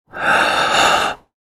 Deep-inhale-sound-effect.mp3